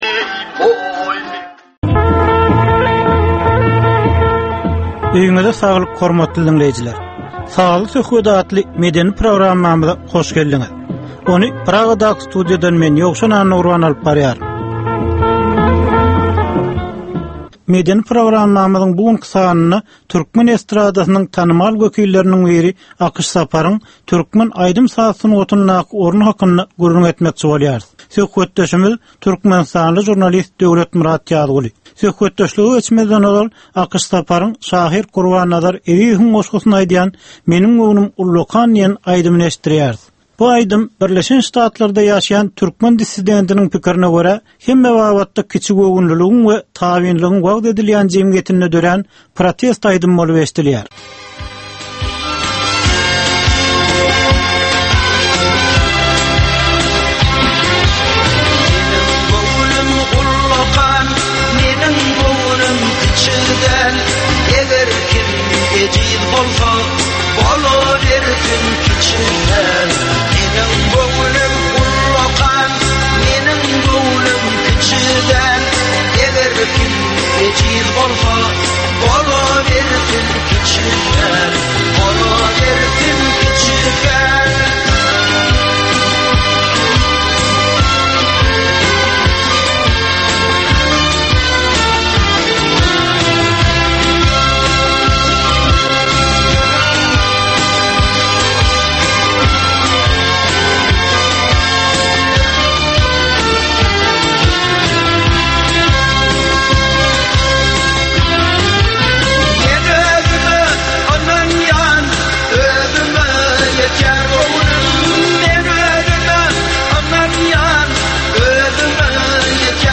Türkmenin käbir aktual meseleleri barada 30 minutlyk sazly-informasion programma